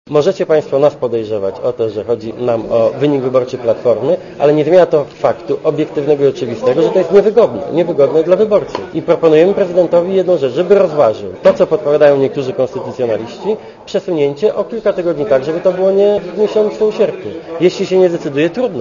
Posłuchaj komentarza Donalda Tuska
Szef Platformy Donald Tusk powiedział na środowej konferencji prasowej w Sejmie, że jego partia nie poprze rządu Marka Belki.